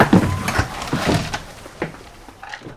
fueltank.ogg